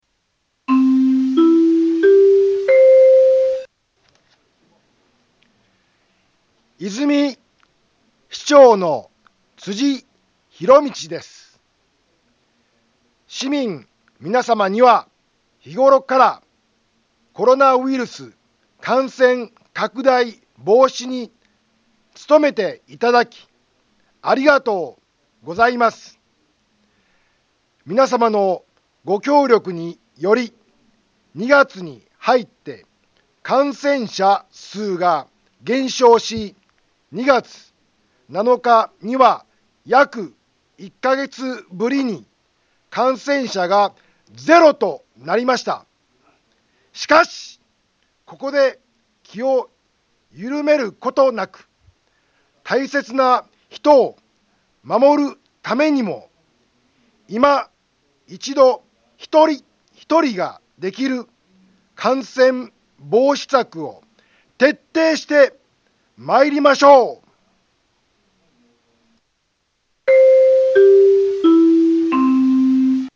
Back Home 災害情報 音声放送 再生 災害情報 カテゴリ：通常放送 住所：大阪府和泉市府中町２丁目７−５ インフォメーション：和泉市長の、辻 ひろみちです。